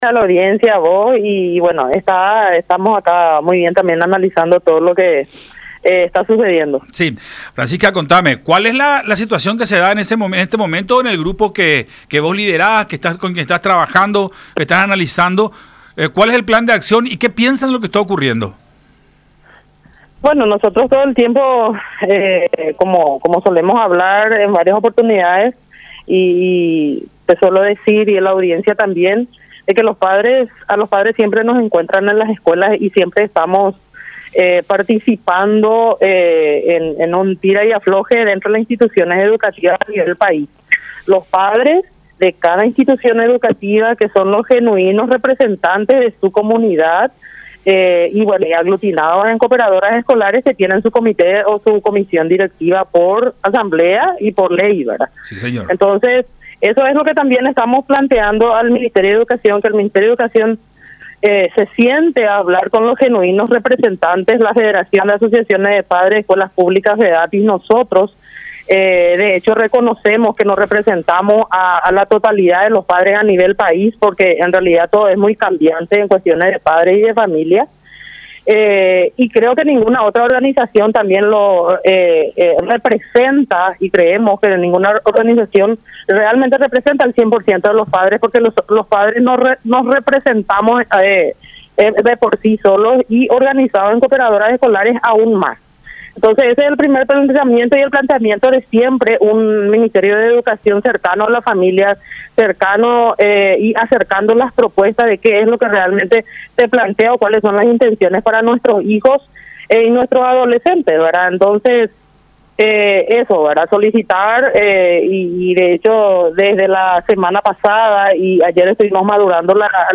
en diálogo con Buenas Tardes La Unión por Unión TV y radio La Unión